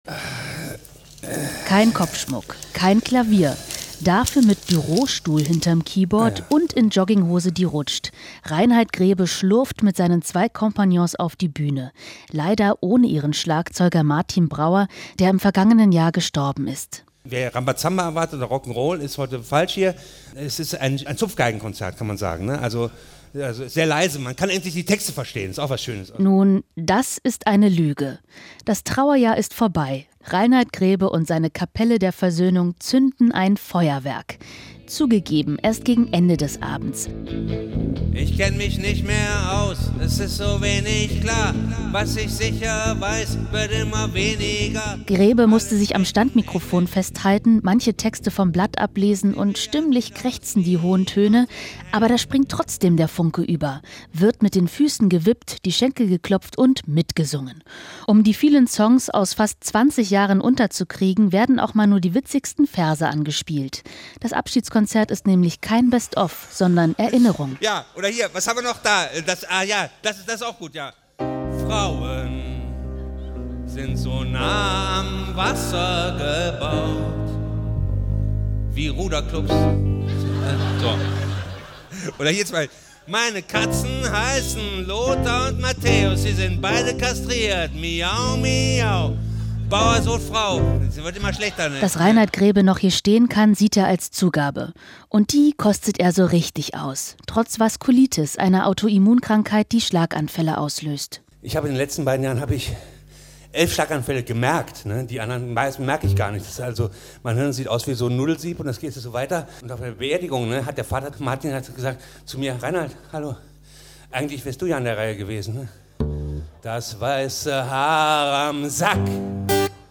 Es war ein Abschiedskonzert mit der Kapelle der Versöhnung.